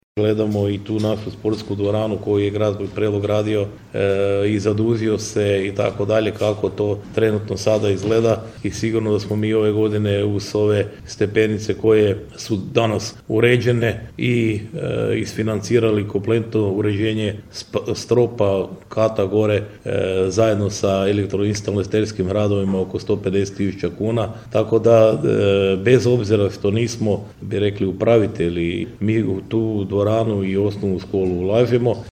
Prelog će iduće godine u listopadu biti domaćin Prvenstvu Hrvatske u karateu, najavljeno je ovog tjedna na održanoj konferenciji za medije u gradskoj vijećnici.
Samim time gradonačelnik Ljubomir Kolarek osvrnuo se stanje i ulaganja u sportskoj dvorani: